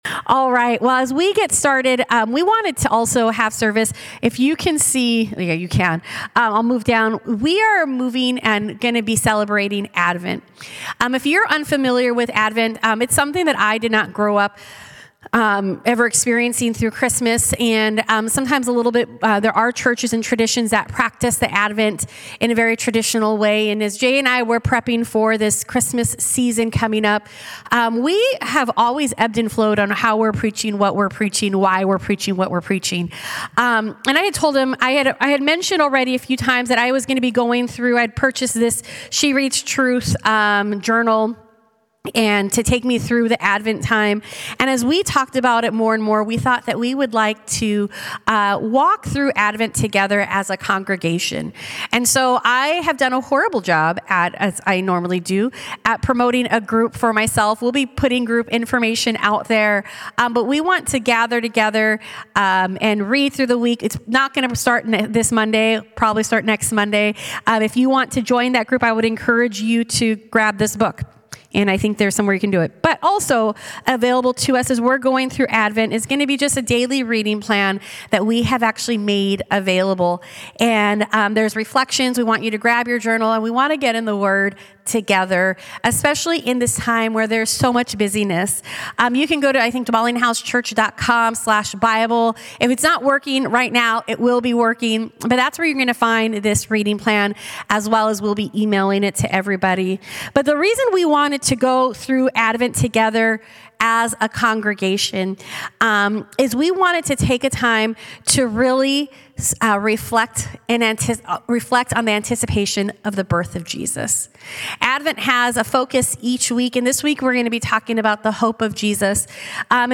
Message: "The Light of Jesus"